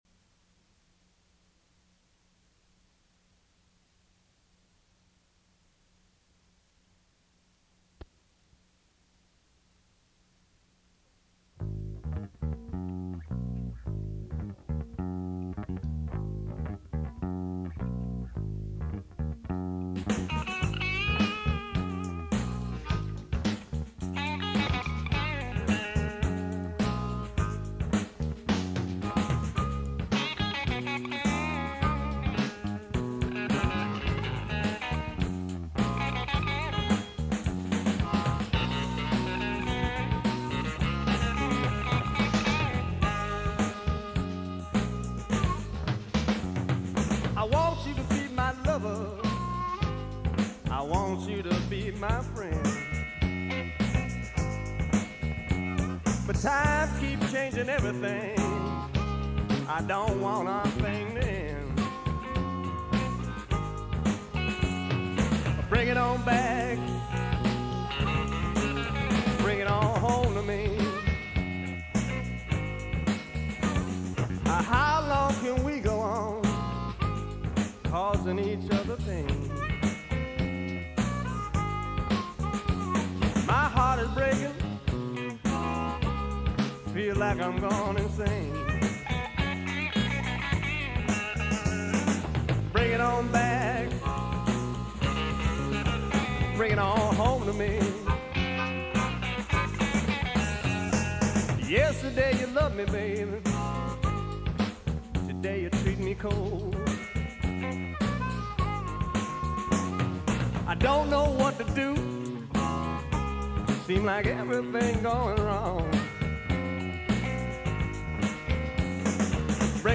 Blues: